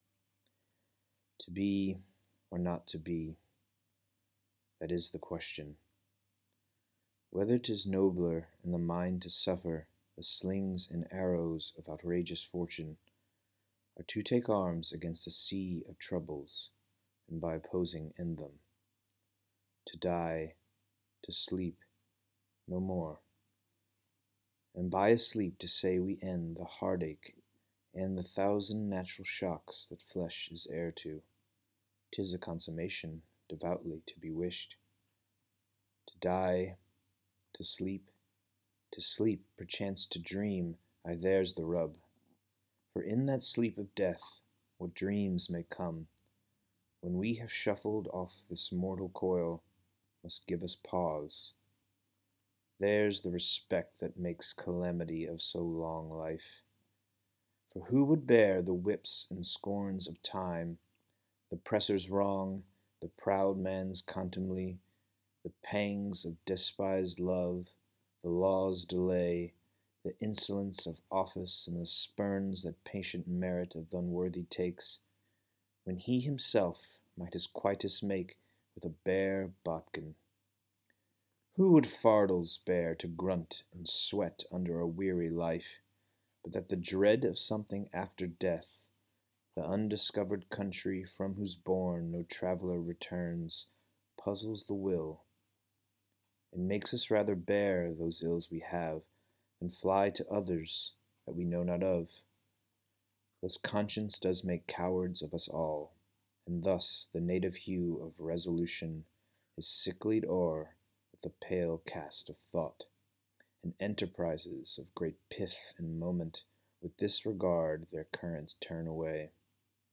Please note that you cannot choose the “To be or not to be” speech, because I chose it! See the sample scansion below, my recitation, and my audio commentary.